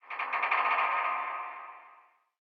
ambienturban_26.ogg